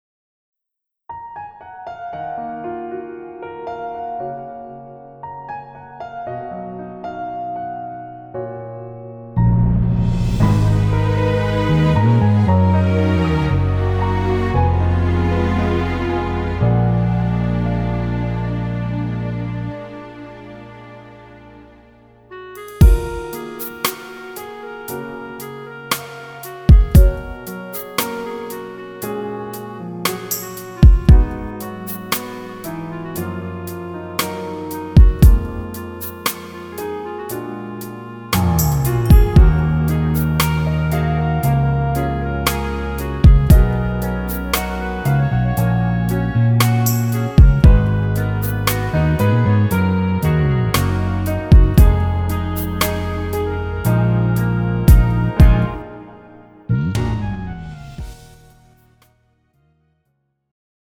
장르 가요 구분